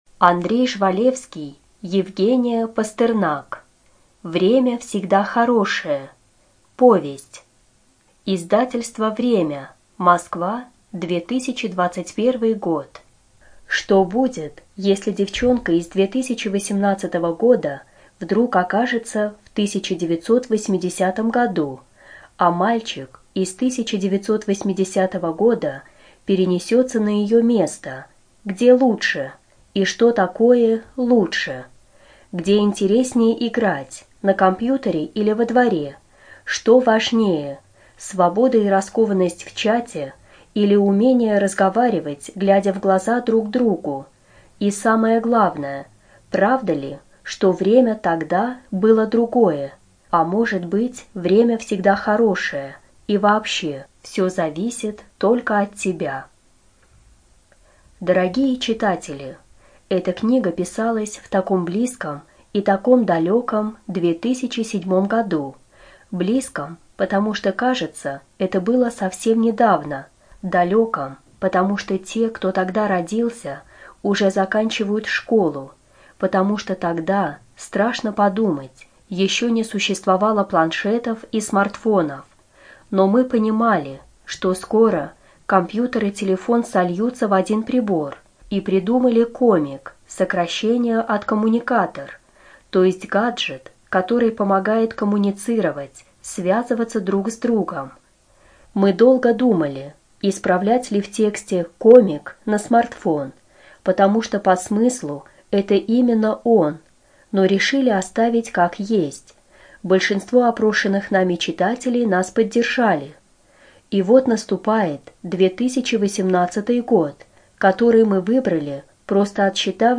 ЖанрФантастика, Детская литература
Студия звукозаписиБелгородская областная библиотека для слепых имени Василия Яковлевича Ерошенко